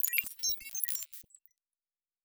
Data Calculating 3_1.wav